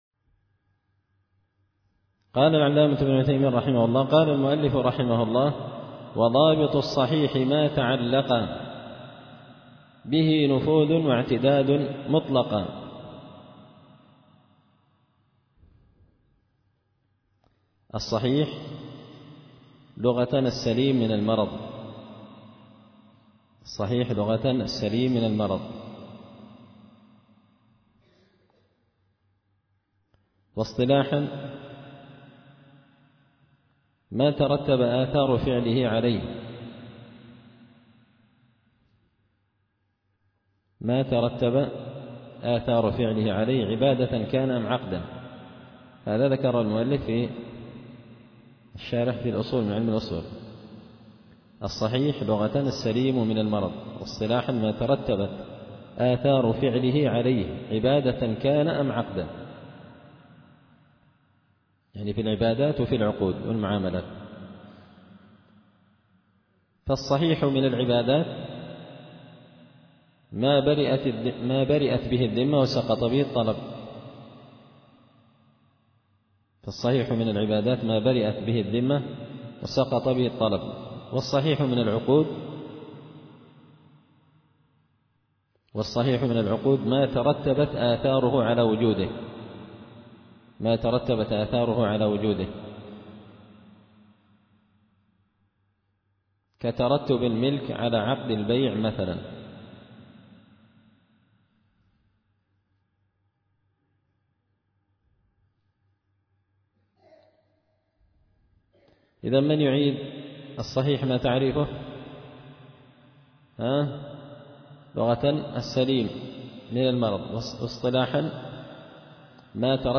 التعليقات على نظم الورقات ـ الدرس 6
دار الحديث بمسجد الفرقان ـ قشن ـ المهرة ـ اليمن